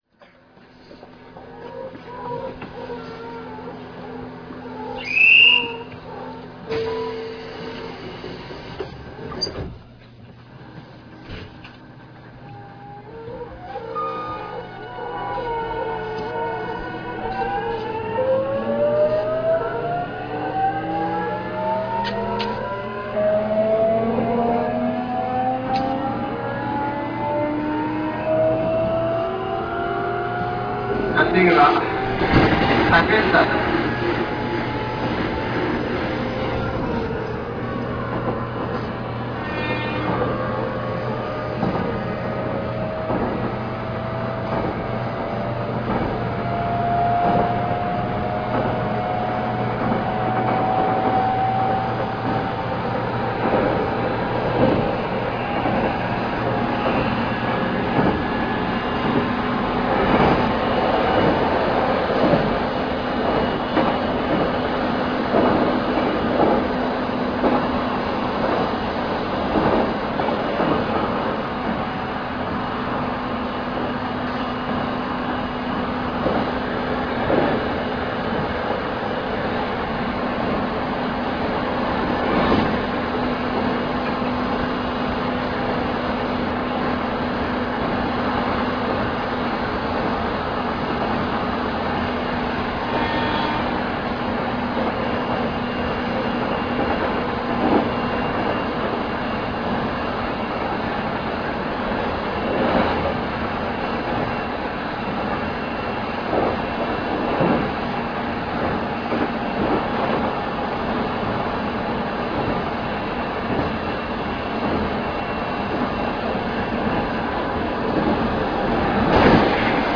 200番代走行音[813-2a.ra/311KB]
制御方式：VVVFインバータ制御(東芝GTO・個別制御、PC400K型)